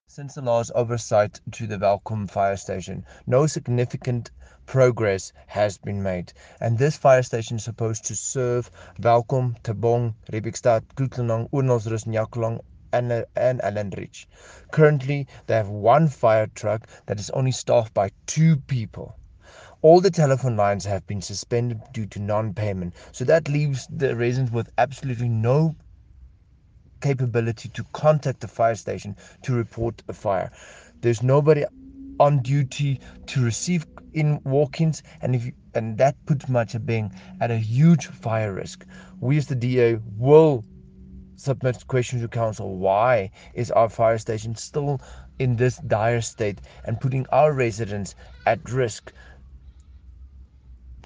Afrikaans soundbites by Cllr Igor Scheurkogel and